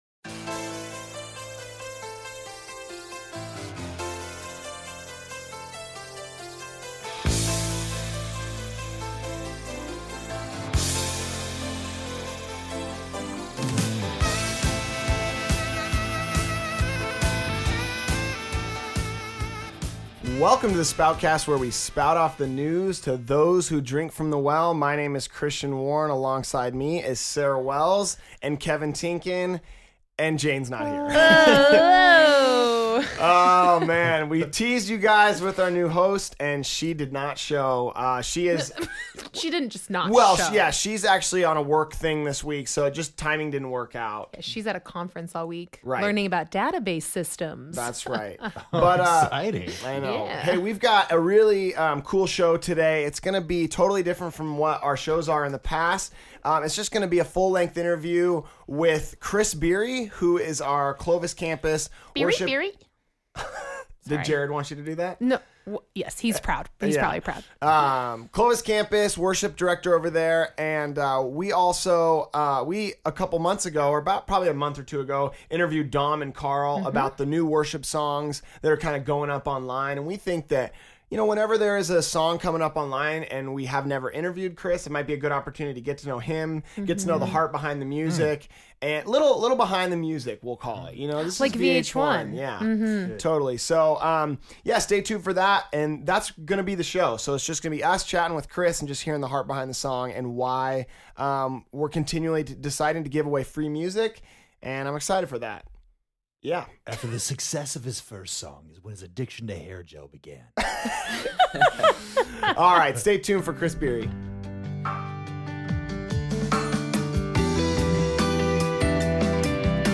We are going to play an extended interview with people from our Worship Department.